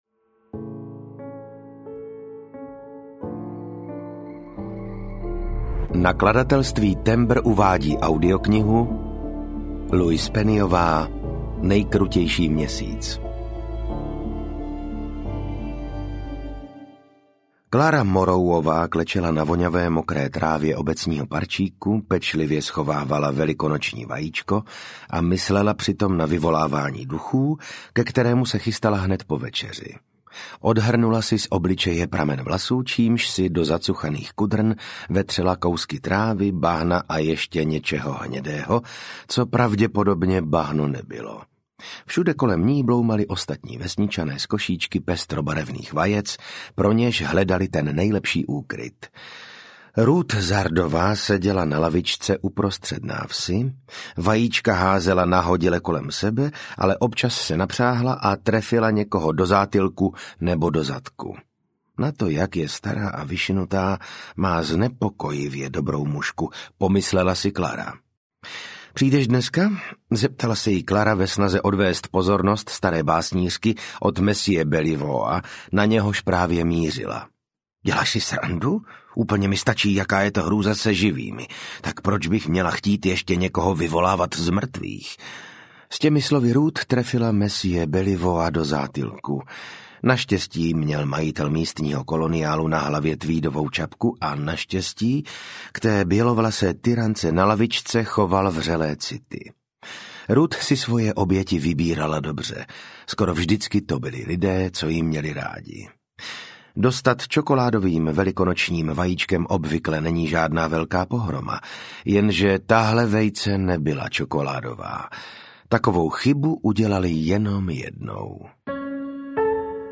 Nejkrutější měsíc audiokniha
Ukázka z knihy
• InterpretVasil Fridrich